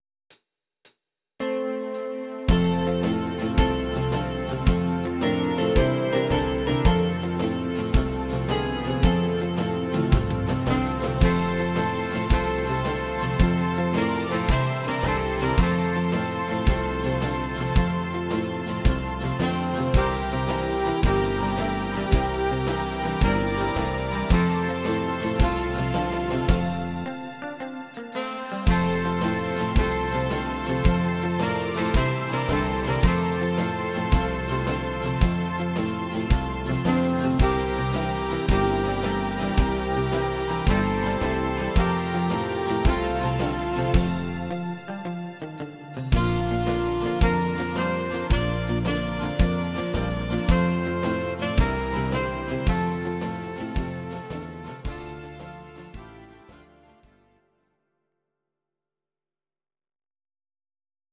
Audio Recordings based on Midi-files
instr. Saxophon